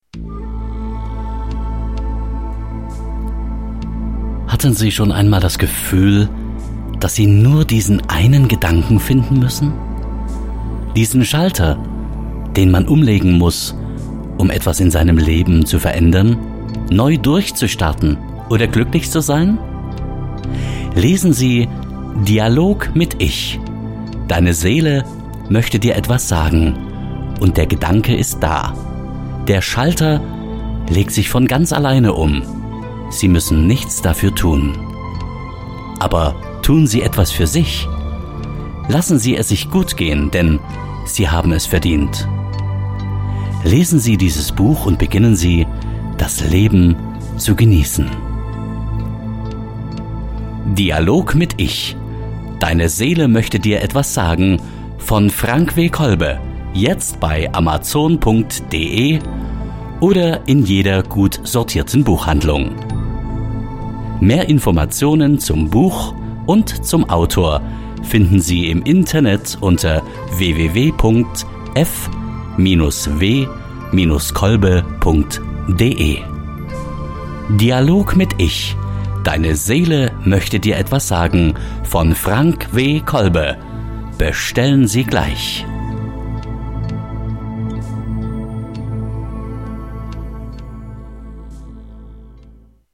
Hier hören Sie die Audiospur des Werbevideos für das Buch “Dialog mit Ich”. Hintergrundmusik und Text wurden vom Kunden geliefert.